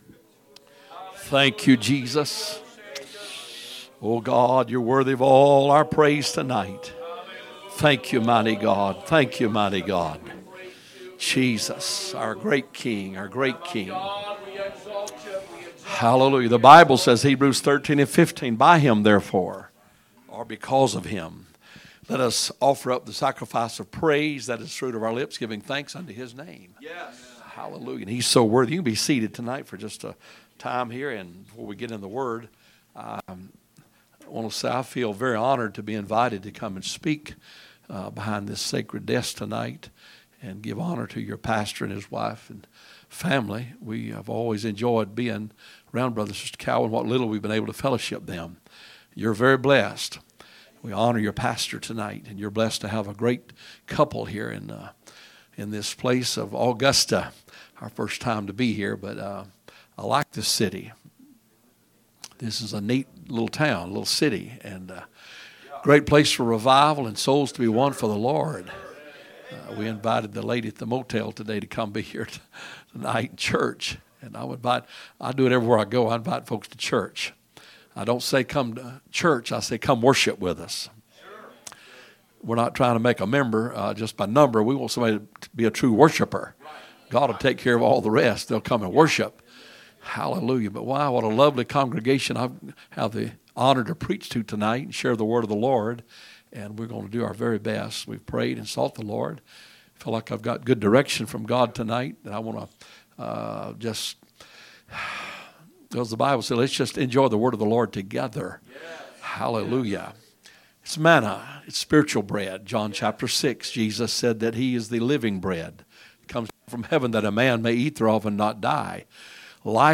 Friday Message - Special